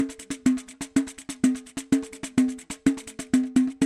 桑巴打击乐 " X Pandeiro 2 Bar
描述：传统桑巴乐器的循环播放
Tag: 回路 pandeiro